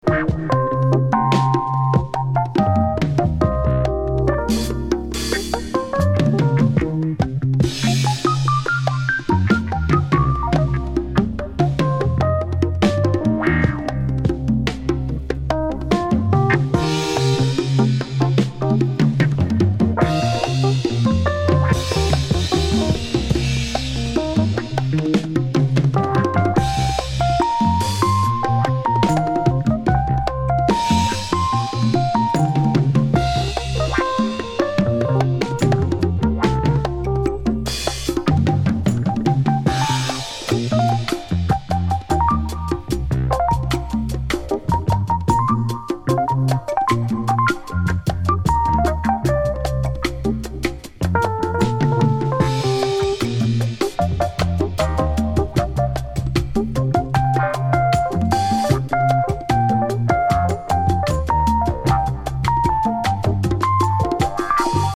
with a brass section, a string section, and vocalists
Soul Funk